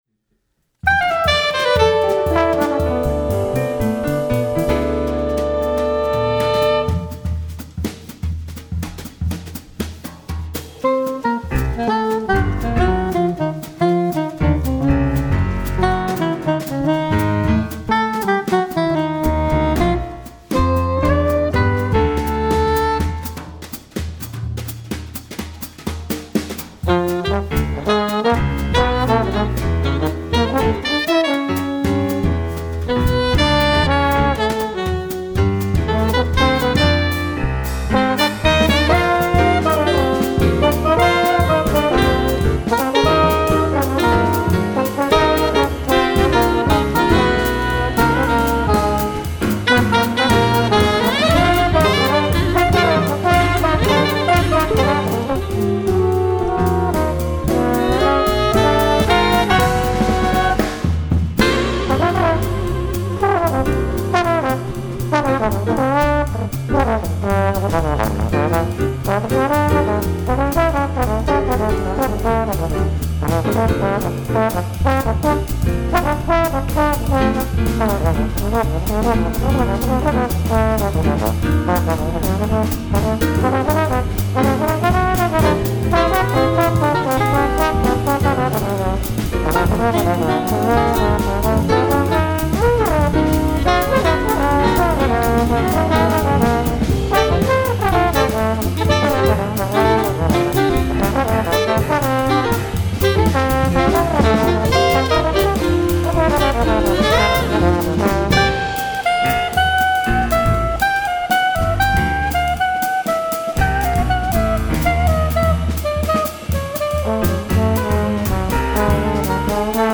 (Multi-track recording)